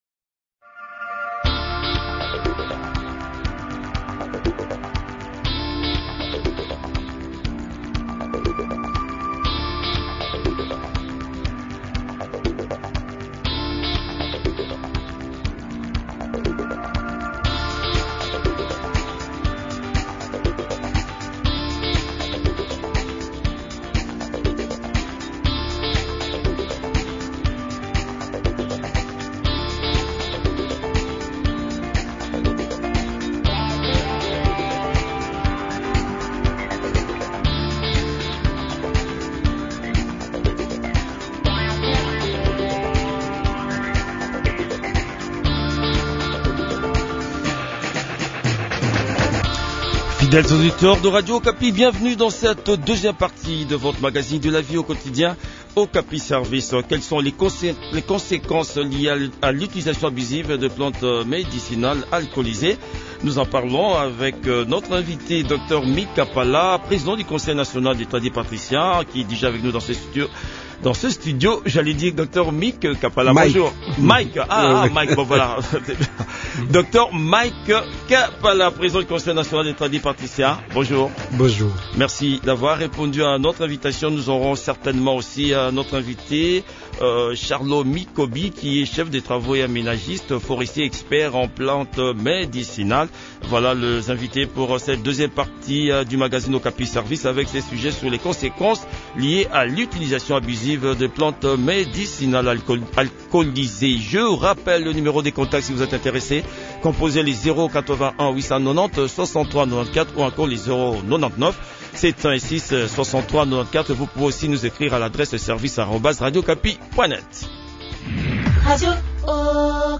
aménagiste forestier et expert en plantes médicinales a aussi pris part à cet entretien.